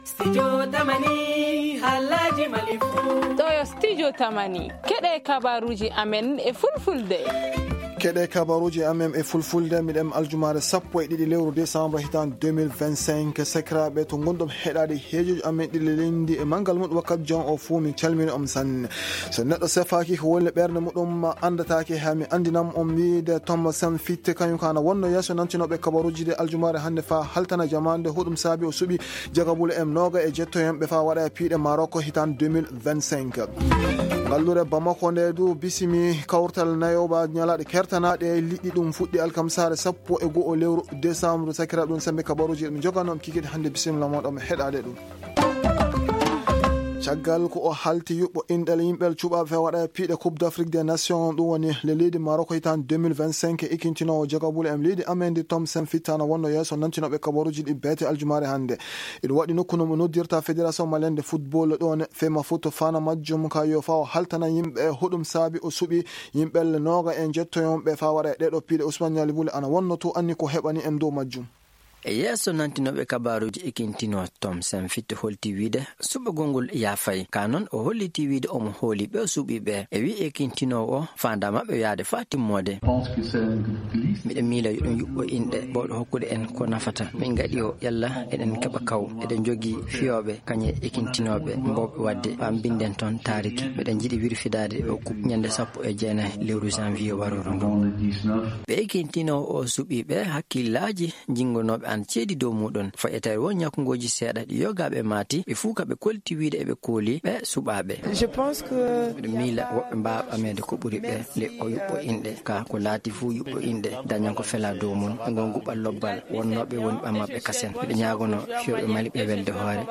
Le journal en Peulh du 12 décembre 2025